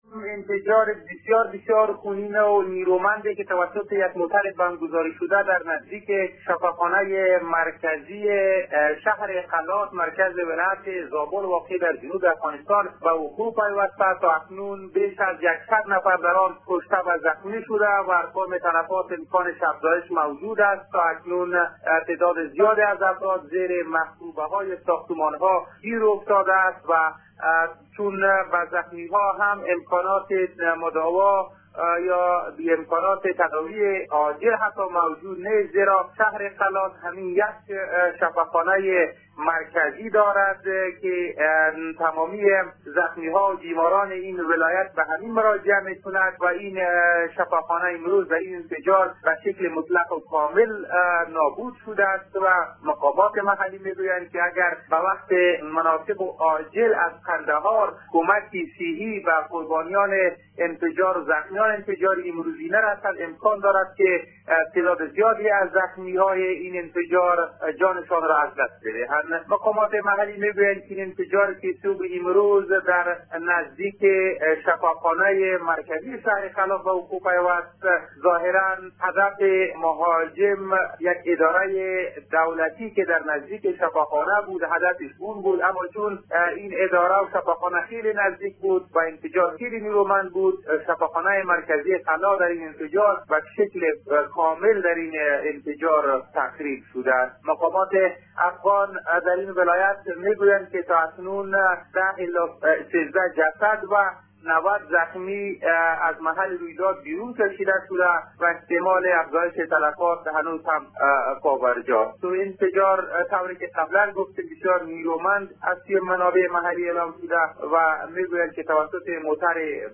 جزئیات در گزارش خبرنگار رادیودری: